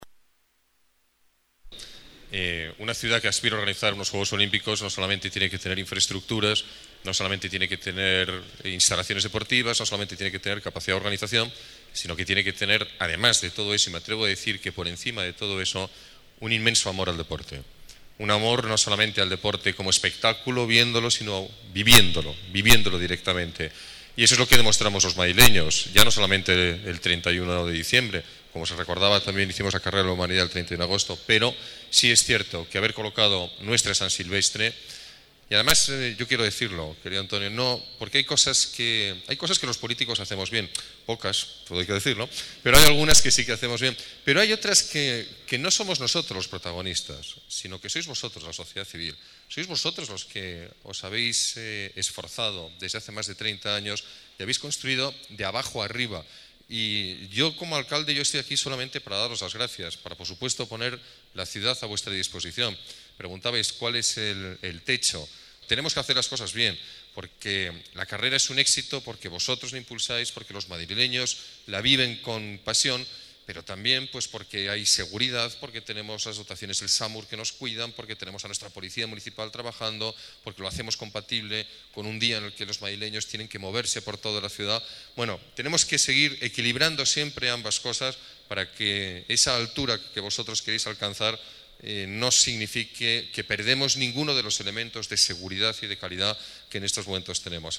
Nueva ventana:Declaraciones alcalde, Alberto Ruiz-Gallardón: San Silvestre Vallecana